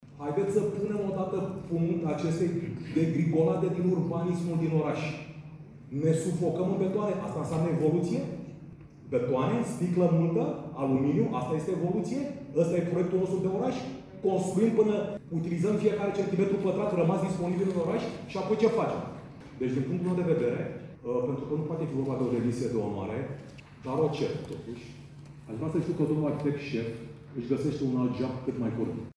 Repoter